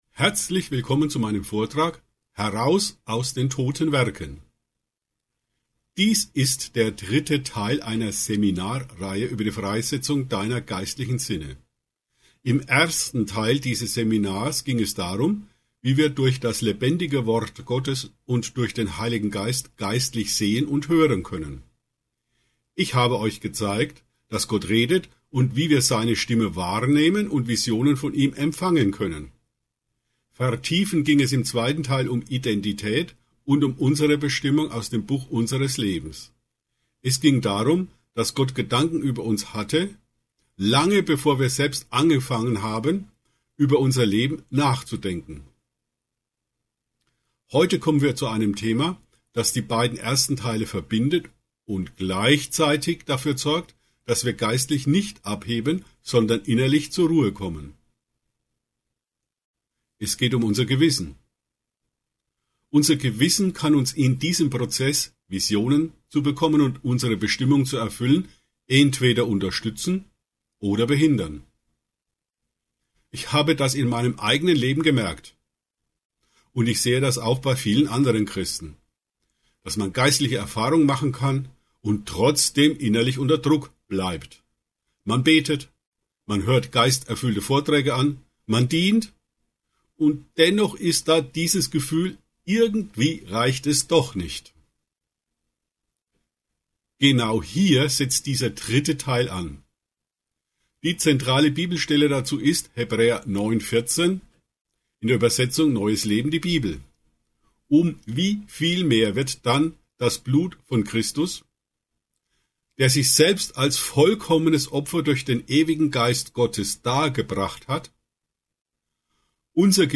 Beschreibung vor 1 Woche Kennst du das Gefühl, viel für Gott zu tun und innerlich trotzdem nicht zur Ruhe zu kommen? Dieses Seminar lädt dazu ein, ehrlich hinzuschauen, wo fromme Aktivität zur Last werden kann – und wie Gott einen anderen Weg eröffnet. Im Mittelpunkt steht Hebräer 9,14 und die befreiende Wahrheit, dass Christus nicht nur unsere Schuld vergibt, sondern unser Gewissen reinigt.